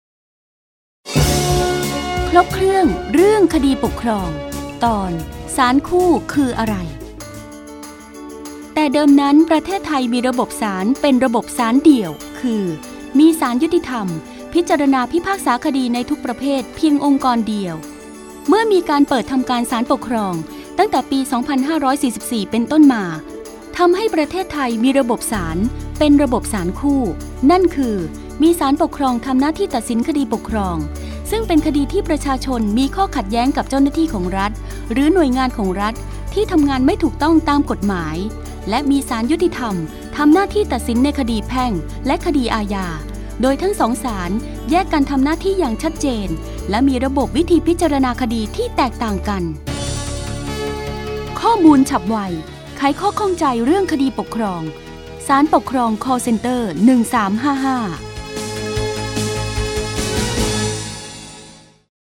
สารคดีวิทยุ ชุดครบเครื่องเรื่องคดีปกครอง ตอนศาลคู่คืออะไร